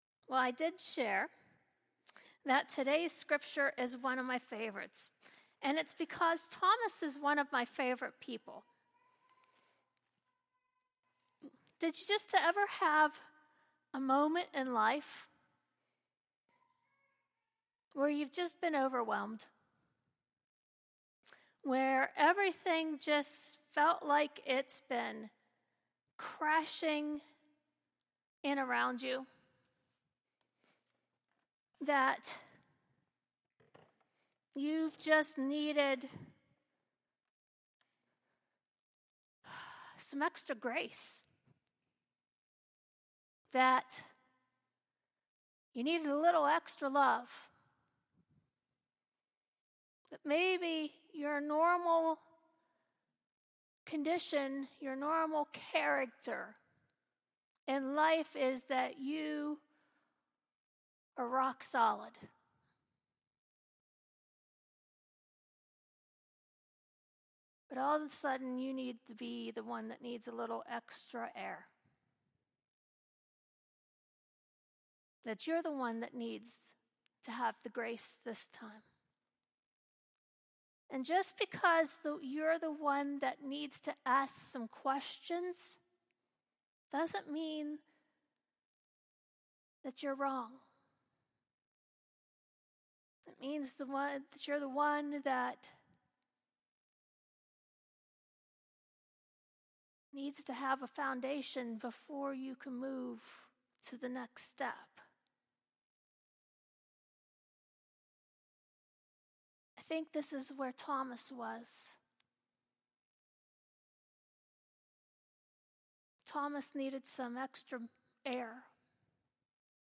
Scripture Reading – John 20:19-31
Morning Message – “Grace for the Doubting”